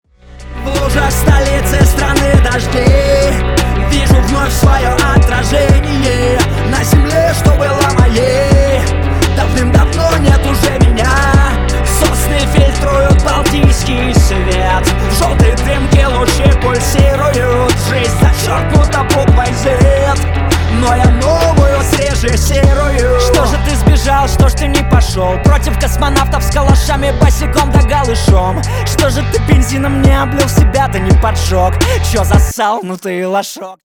• Качество: 320, Stereo
русский рэп
качающие